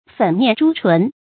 粉面朱唇 注音： ㄈㄣˇ ㄇㄧㄢˋ ㄓㄨ ㄔㄨㄣˊ 讀音讀法： 意思解釋： 白面紅唇。